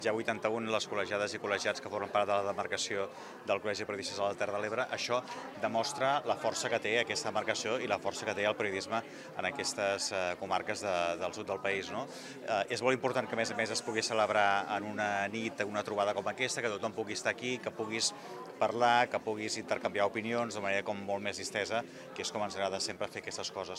La cinquena edició de la Nit de la Comunicació, organitzada per la Demarcació de Terres de l’Ebre del Col·legi de Periodistes de Catalunya, va ser aquest passat divendres una gran festa de retrobament i de reconeixement de la professió.